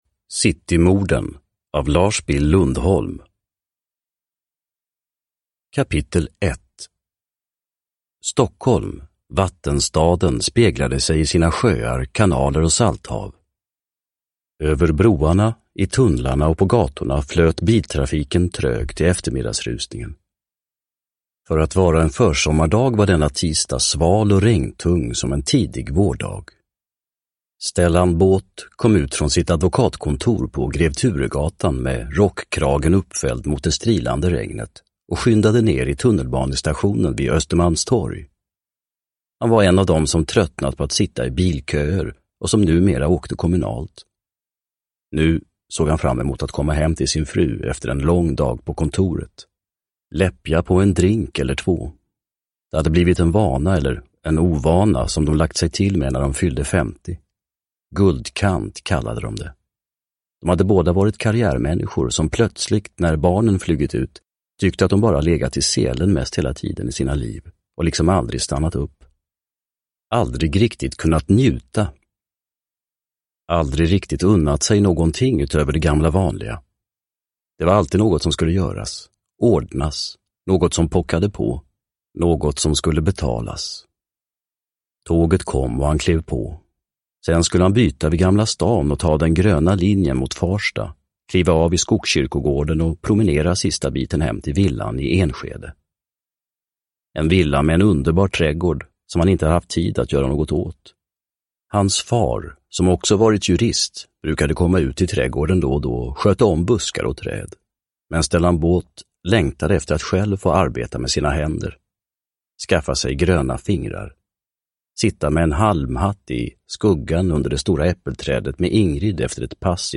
Citymorden / Ljudbok